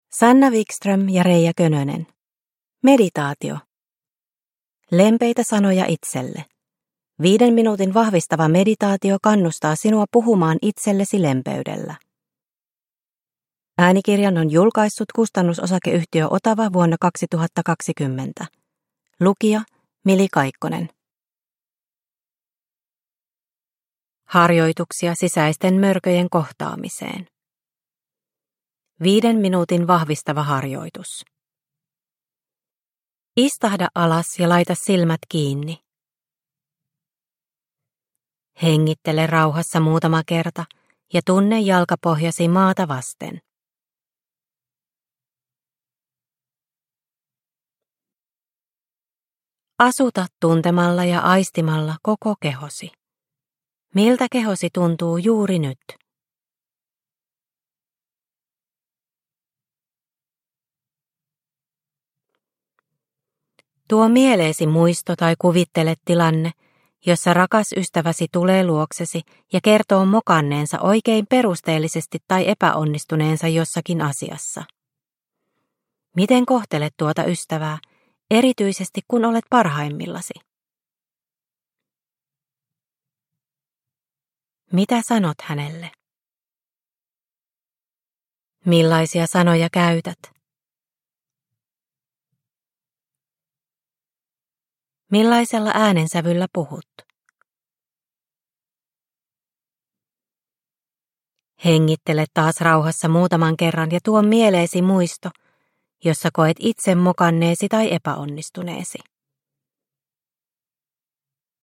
Meditaatio - Lempeitä sanoja itselle – Ljudbok – Laddas ner